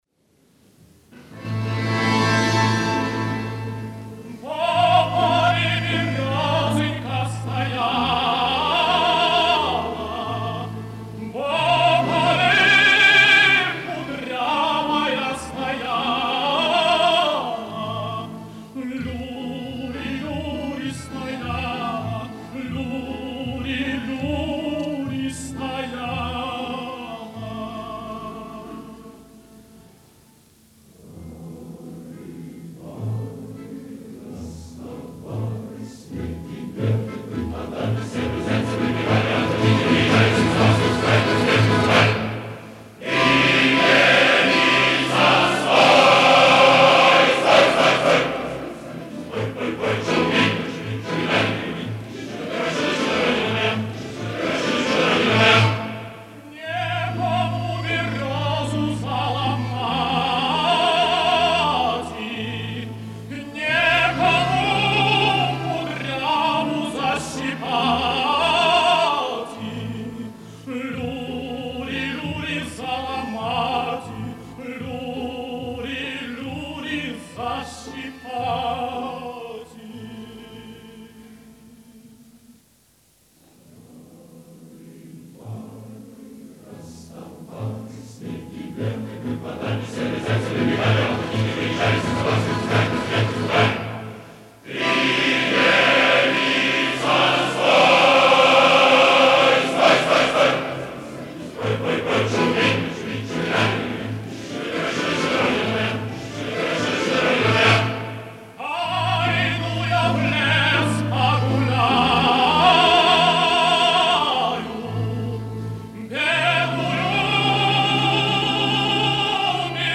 солист